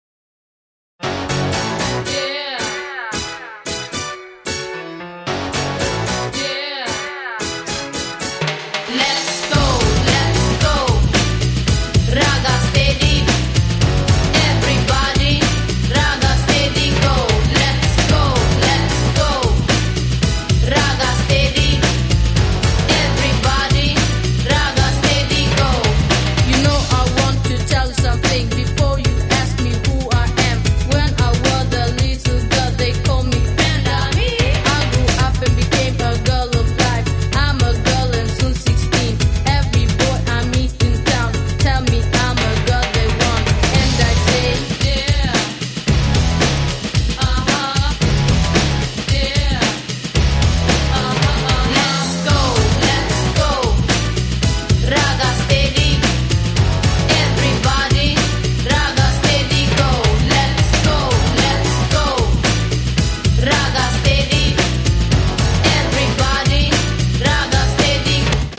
• Качество: 128, Stereo
рэп